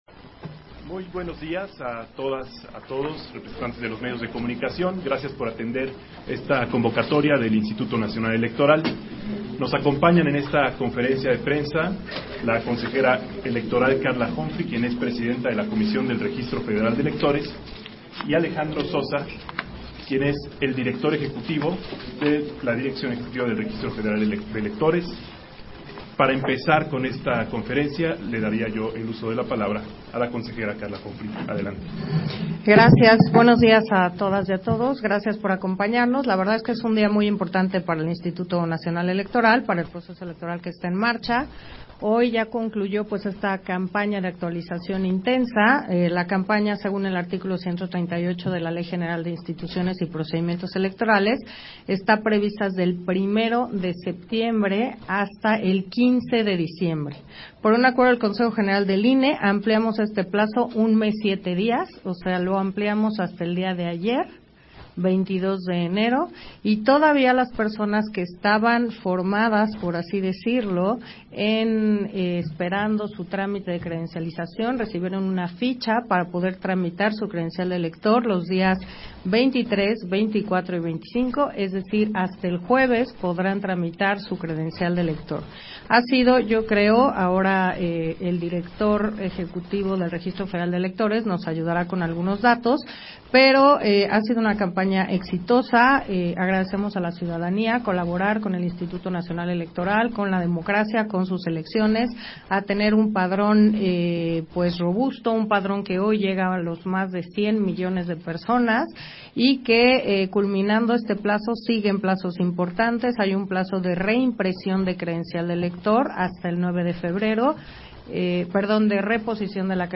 Conferencia de prensa para dar a conocer el proceso de credencialización